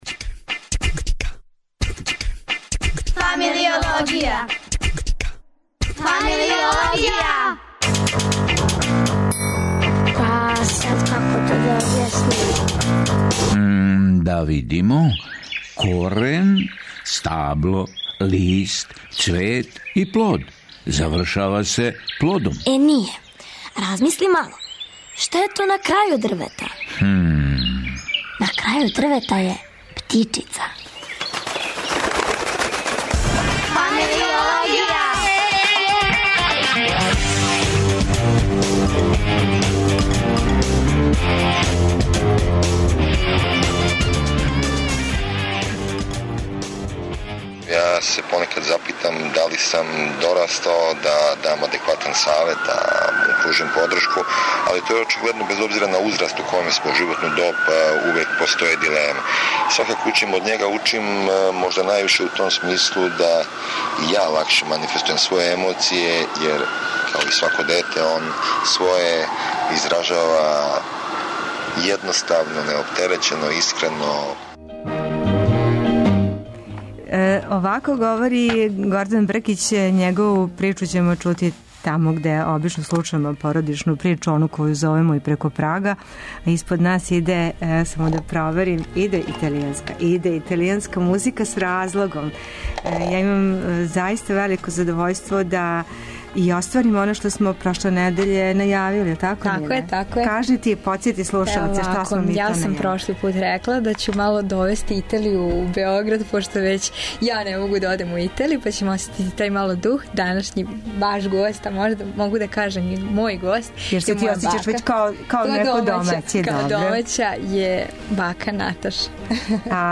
Остатак фотографије, у Фамилиологији, чије су гошће: једна унука и једна бака.